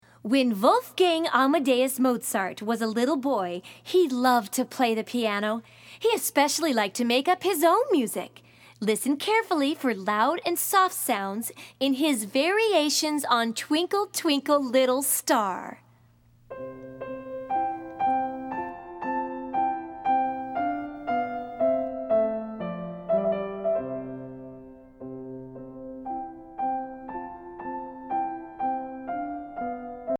Voicing: Piano Method